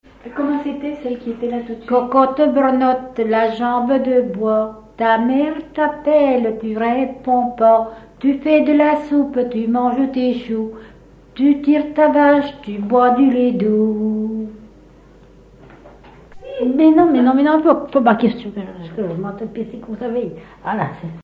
Thème : 0078 - L'enfance - Enfantines - rondes et jeux
Genre brève
Catégorie Pièce musicale inédite